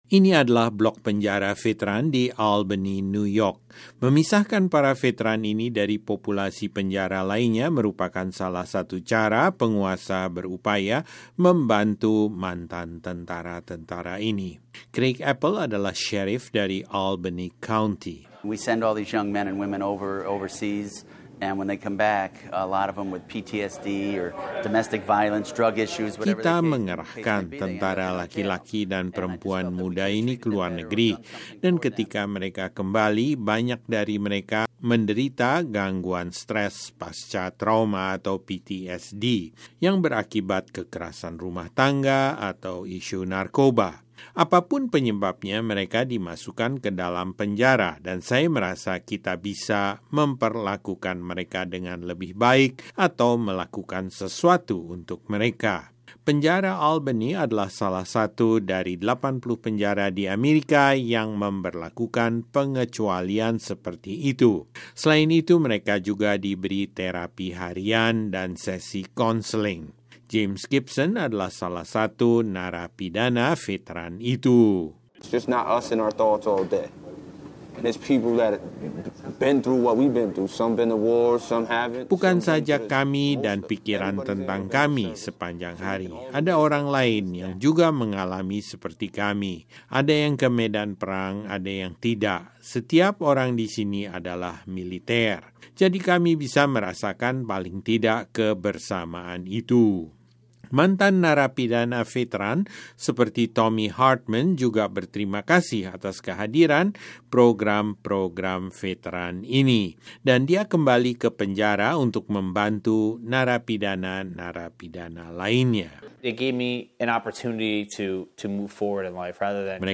Kini beberapa program yang inovatif berupaya membantu. Laporan